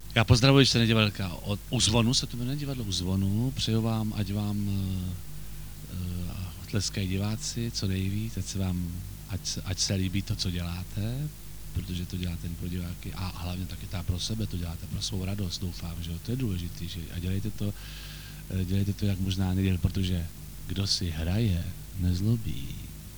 Zdravice-Labus-mono.mp3